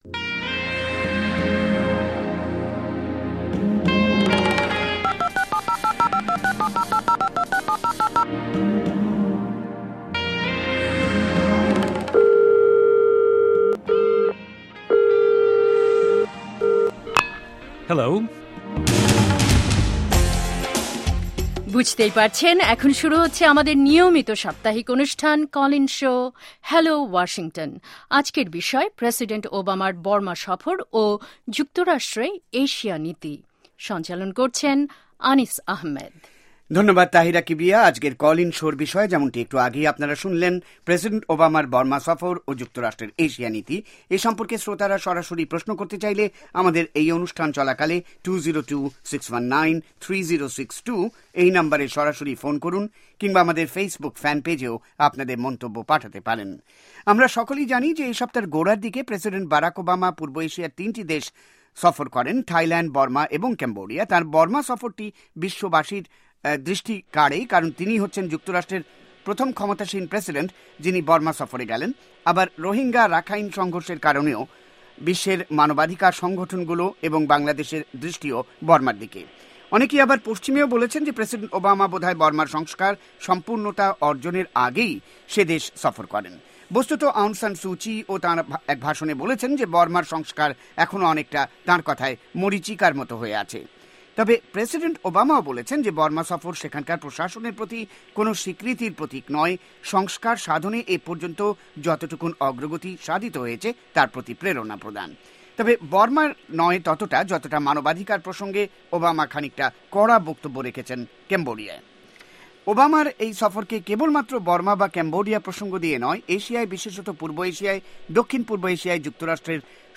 এ সপ্তার কল ইন শো